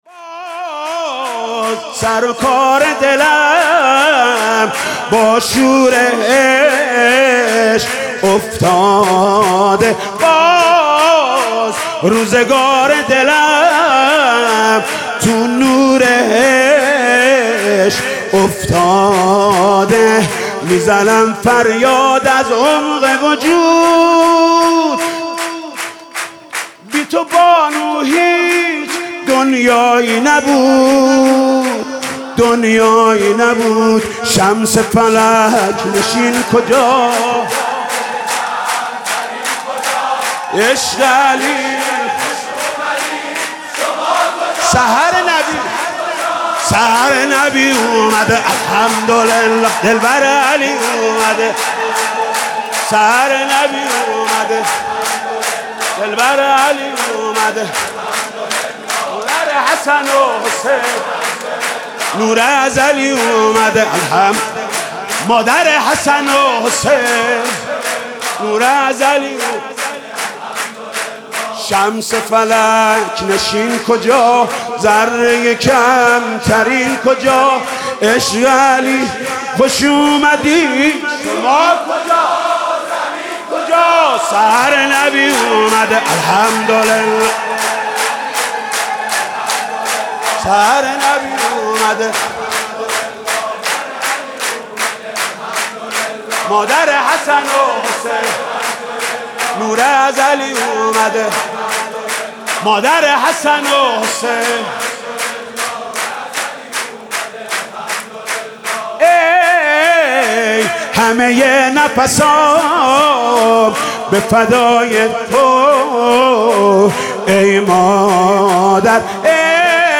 سرود: باز سر و کار دلم با شور عشق افتاده